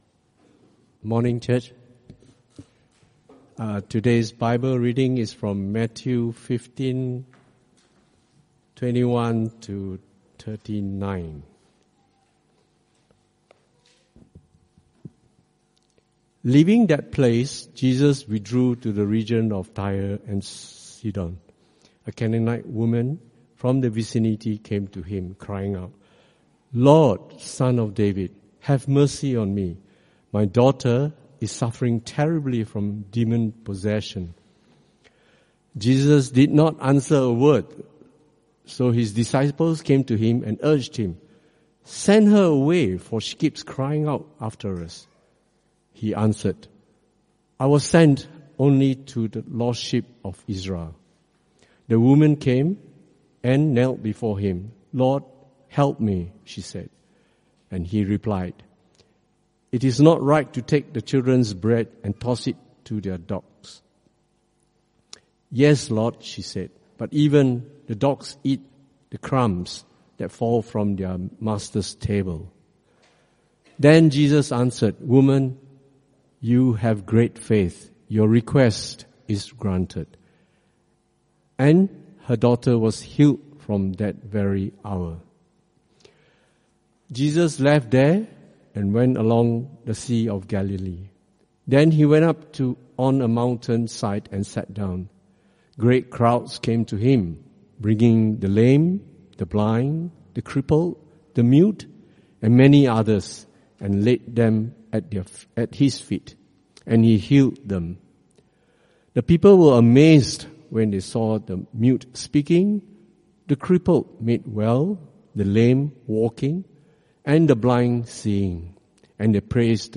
Type: Sermons CBC Service: 13 October 2024